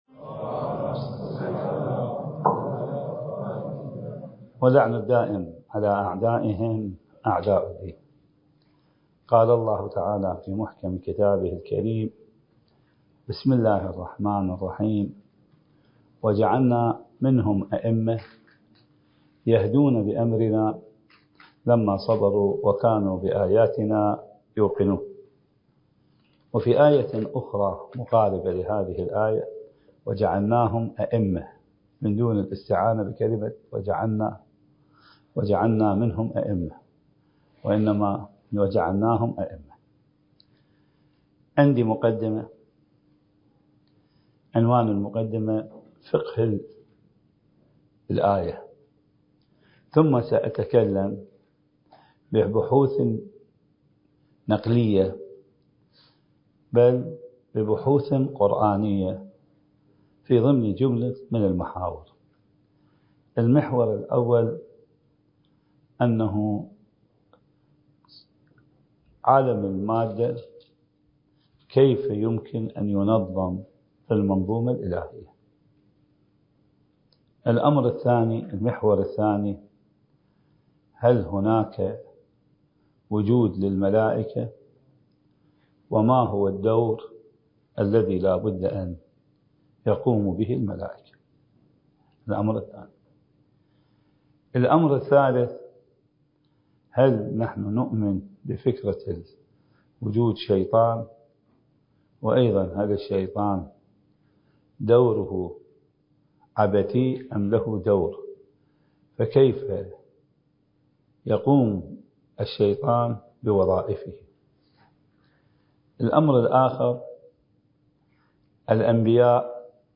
الدورة المهدوية الأولى المكثفة (المحاضرة التاسعة) التي أقامها معهد تراث الأنبياء (عليهم السلام) للدراسات الحوزوية الألكترونية التابع للعتبة العباسية المقدسة وبالتعاون مع مركز الدراسات التخصصية في الإمام المهدي (عجّل الله فرجه) ومدرسة دار العلم للإمام الخوئي (قدس سره) المكان: النجف الأشرف التاريخ:2024